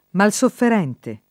vai all'elenco alfabetico delle voci ingrandisci il carattere 100% rimpicciolisci il carattere stampa invia tramite posta elettronica codividi su Facebook malsofferente [ mal S offer $ nte ] o mal sofferente [id.] agg.